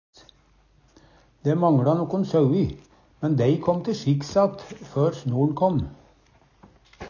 kåmmå te sjikks - Numedalsmål (en-US)